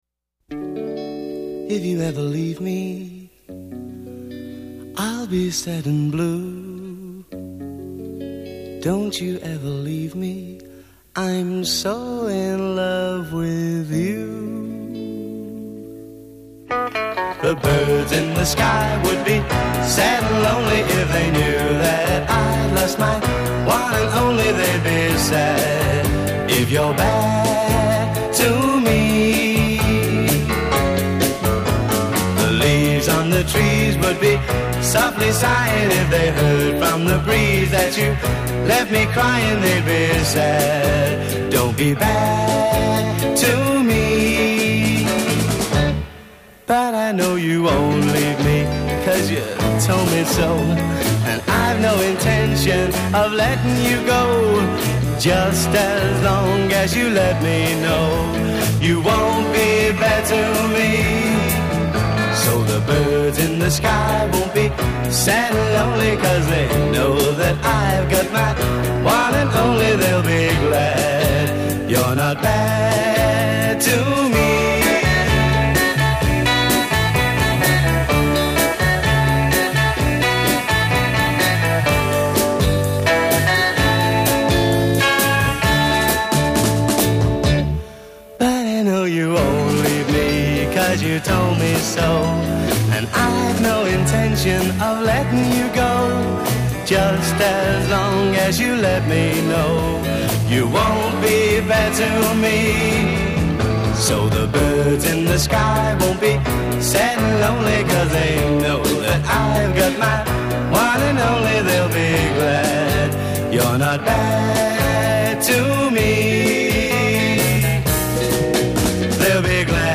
bass guitar
drums
intro 0:00 4 free time guitar and vocal a
guitar-piano counter melody   b
B chorus : 8 single track vocal until hook d
A verse : 8 piano/guitar solo counter melody
B chorus : 8 arpeggiated piano accompaniment d